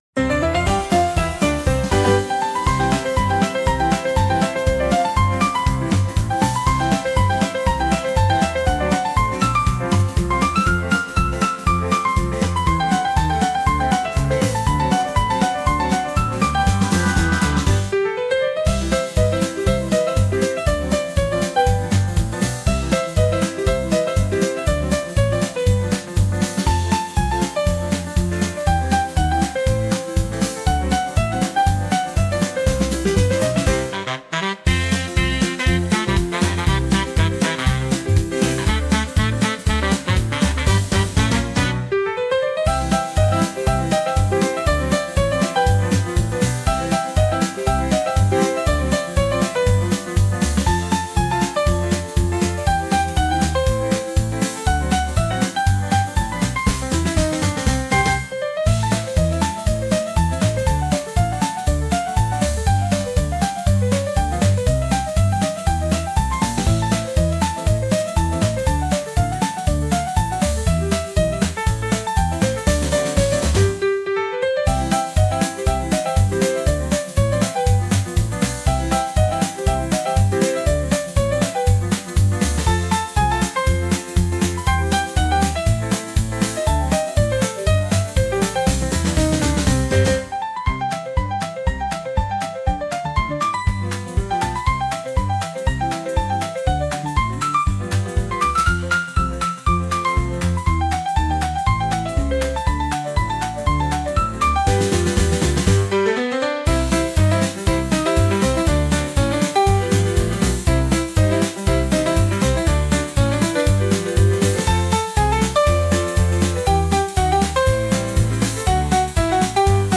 忙し過ぎて楽しくなってきたときのBGM